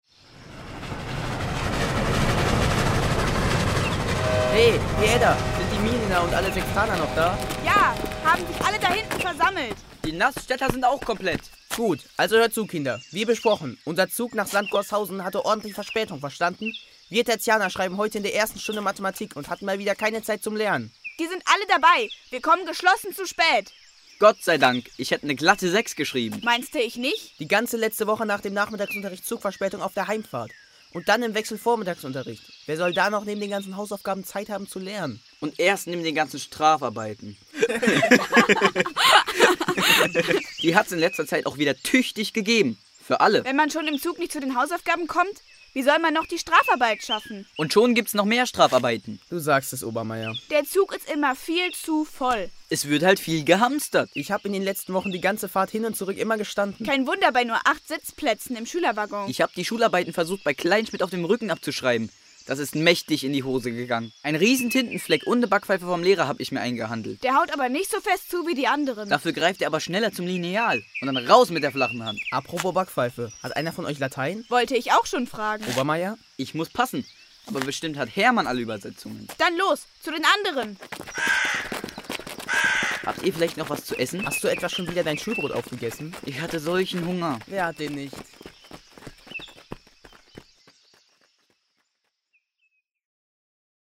Die anschließend eingearbeitete Soundkulisse und die entsprechende Musik schaffen schließlich ein atmosphärisch dichtes Hörspiel – maßgeschneidert zur historischen oder aktuellen Stadtgeschichte.